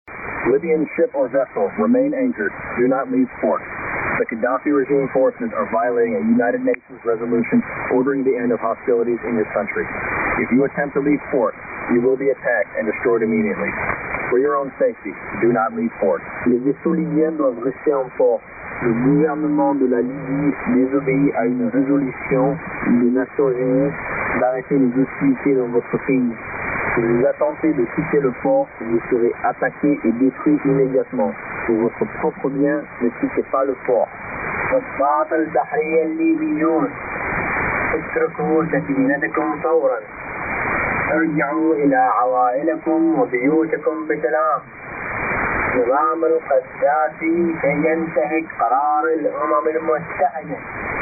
미국 공군 록히드 EC-130J 항공기가 2011년 3월 20일 오디세이 새벽 작전 중 리비아 항구에 해상 봉쇄 경고 메시지를 방송하고 있다.